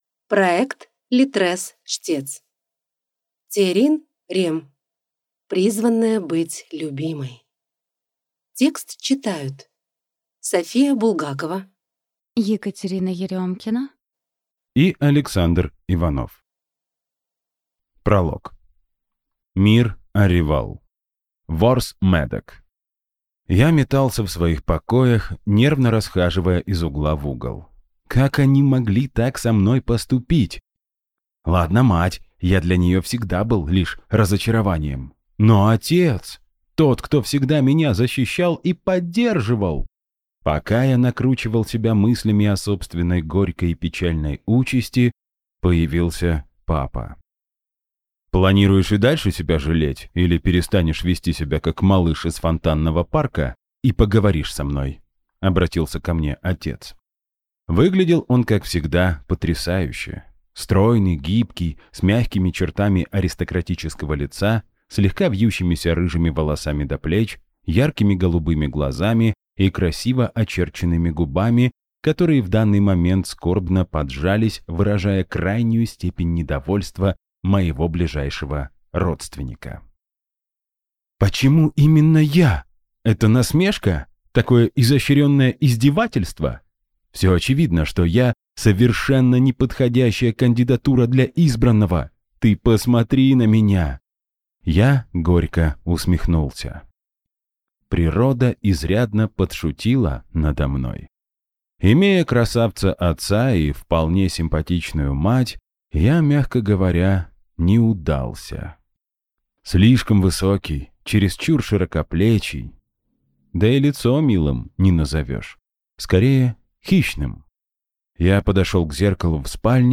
Аудиокнига Призванная быть любимой | Библиотека аудиокниг
Прослушать и бесплатно скачать фрагмент аудиокниги